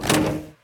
ArrowCrossBowShot-002.wav